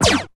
Synth Zaps
Synth Energy Zap, Quick, Mids